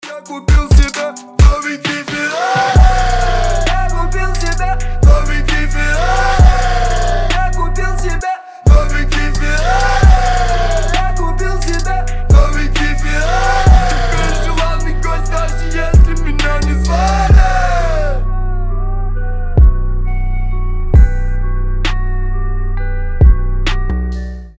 басы